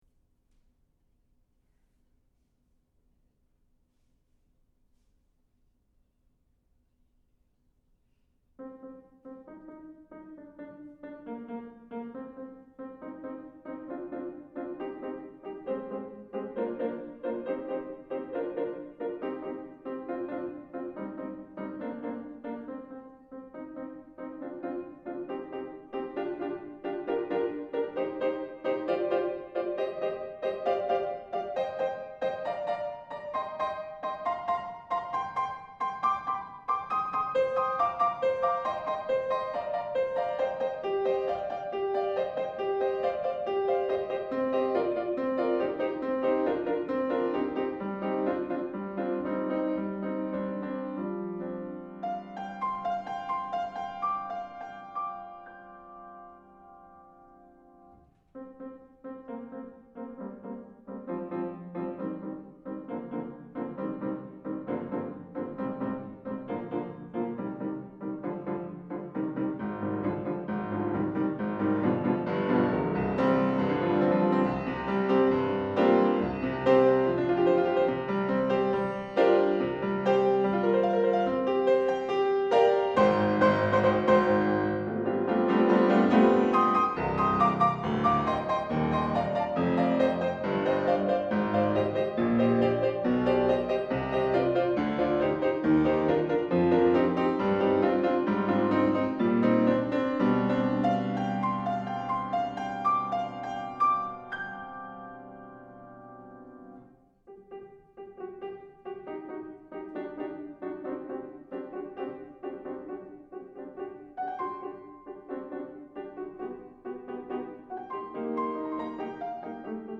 for Piano (2013)